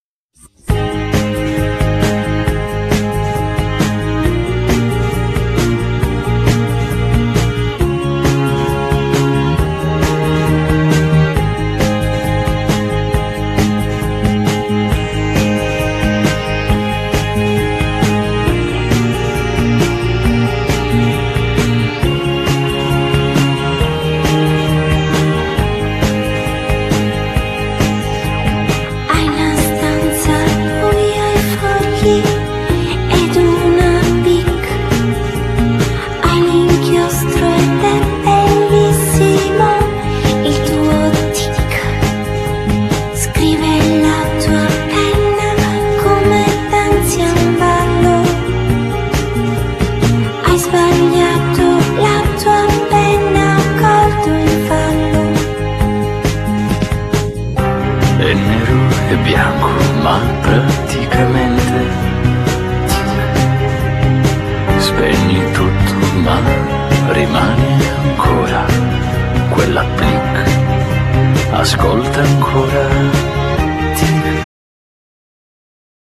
Genere : Electro Pop
Finalmente un disco pop charmant